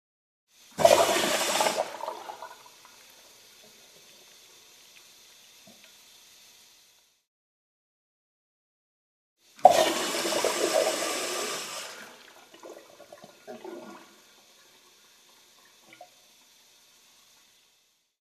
На этой странице собраны звуки, характерные для общественных туалетов: журчание воды, работа сантехники, эхо шагов по кафелю и другие бытовые шумы.
Звуки общественного туалета: писсуар и смыв воды